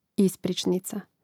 ìspričnica ispričnica